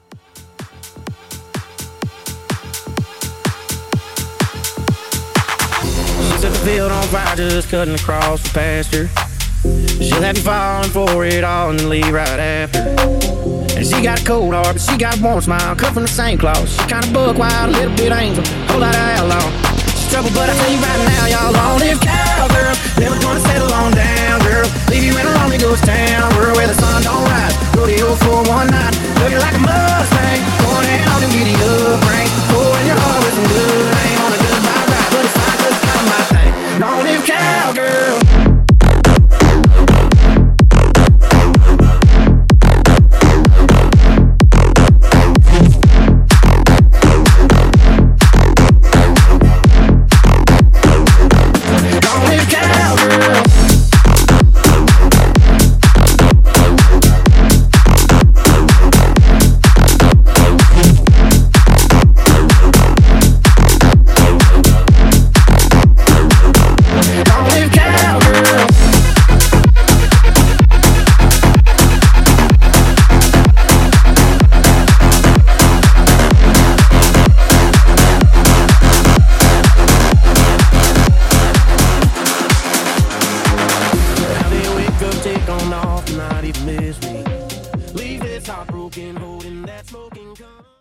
Genres: AFROBEAT , MASHUPS , TOP40
Clean BPM: 126 Time